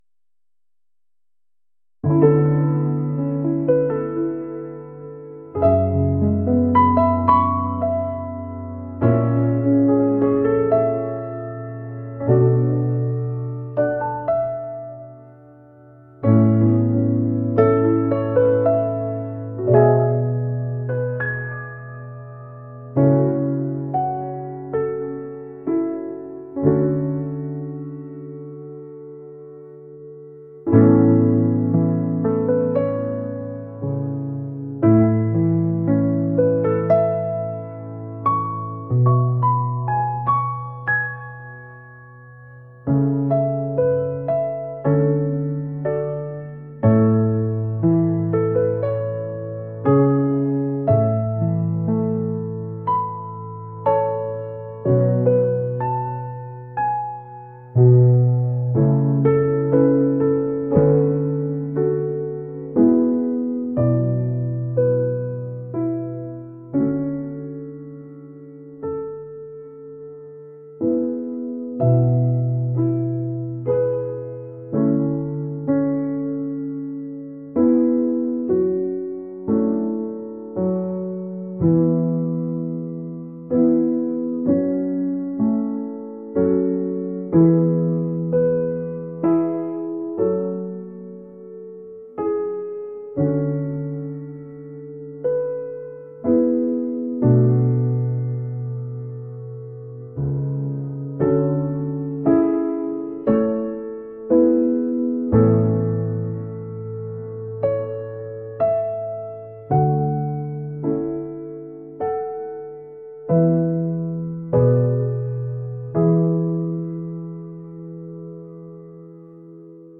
smooth | jazz | mellow